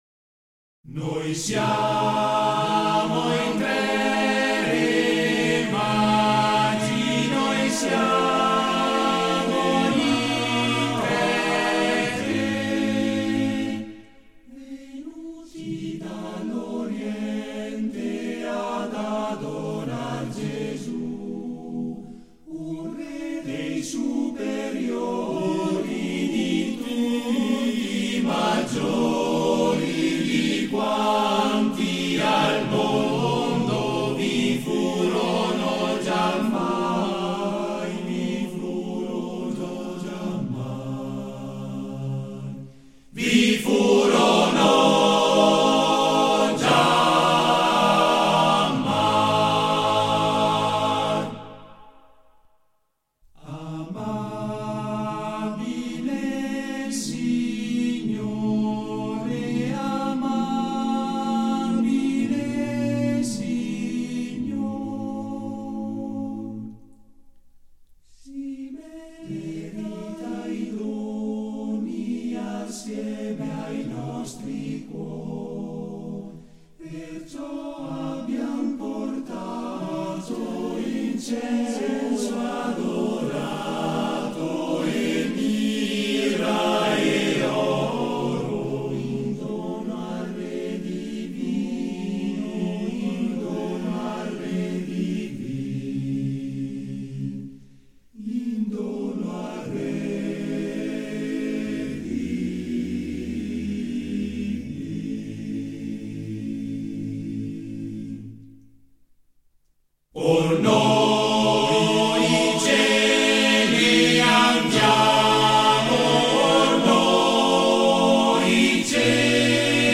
Esecutore: Coro della SAT